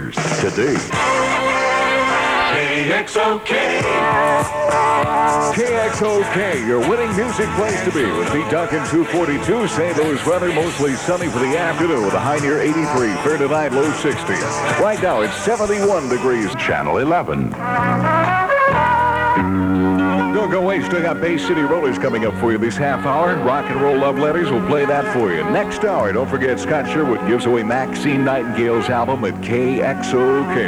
Original Format aircheck